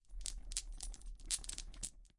最终项目" 08狗项圈的声音
描述：这是一种模拟狗项圈的音频效果
Tag: 项圈 小狗 声音